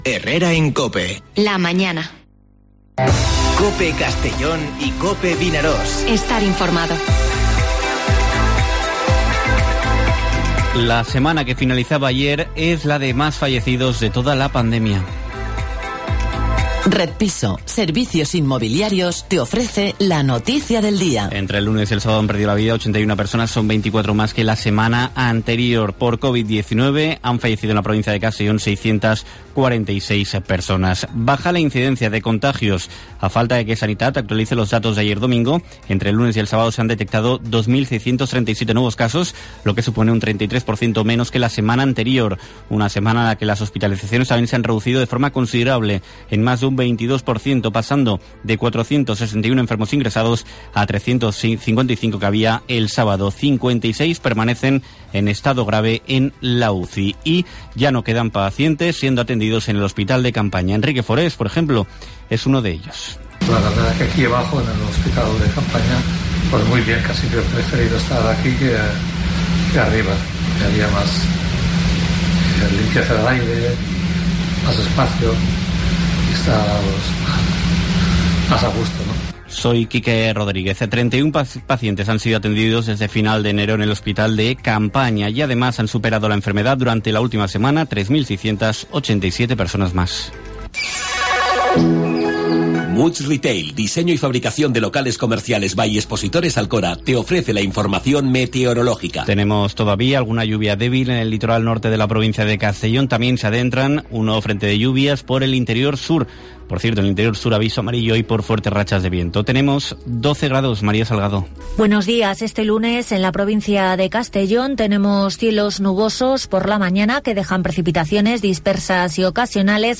Informativo Herrera en COPE en la provincia de Castellón (08/02/2021)